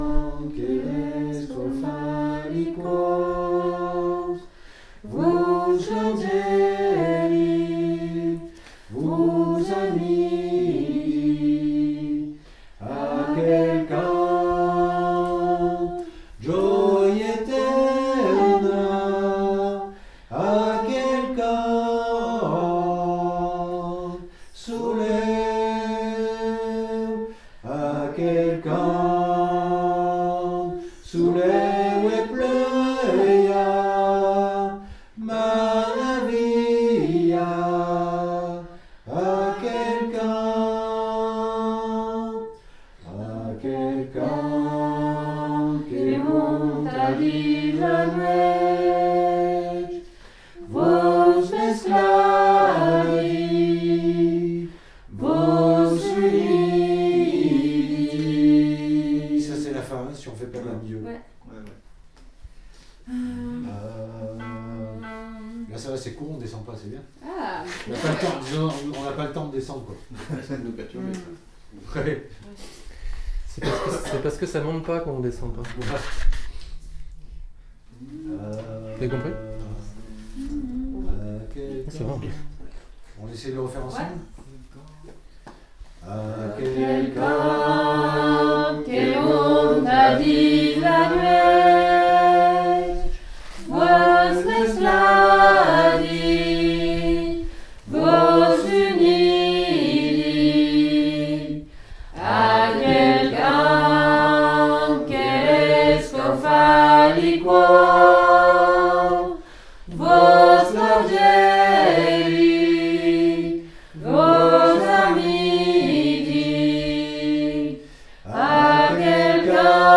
Aquel_cant_VOIX1.mp3